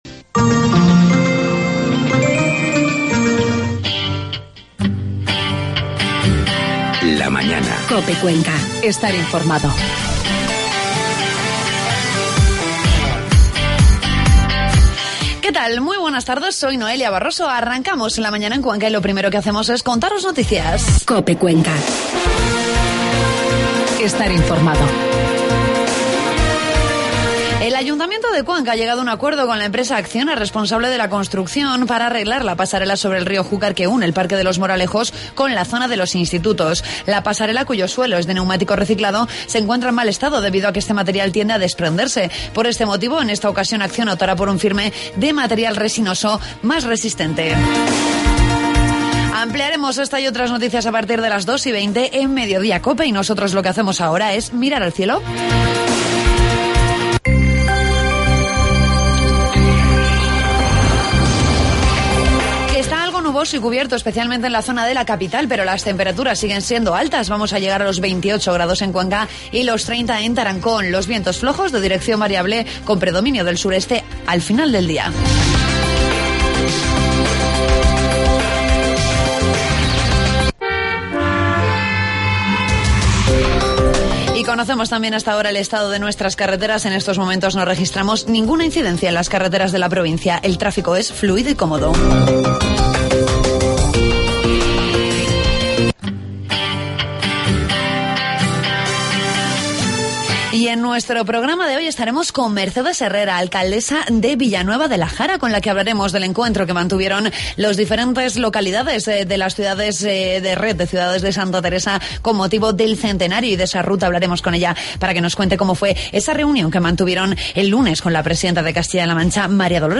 AUDIO: Ya puedes escuchar la tertulia de COPE Cuenca.